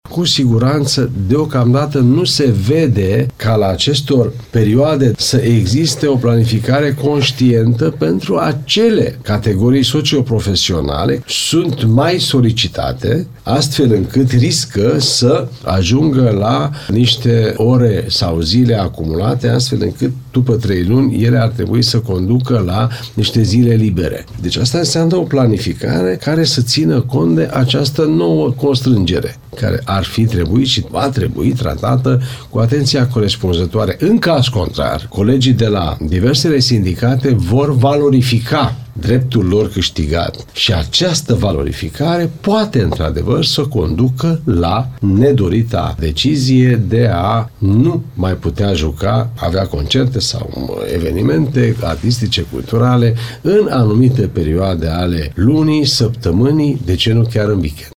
Demeter András a explicat, la Radio Timișoara, că noul CCM pe sectorul cultură și primul pachet de măsuri bugetare arată că munca prestată suplimentar poate fi compensată doar cu zile libere în decurs de 90 de zile.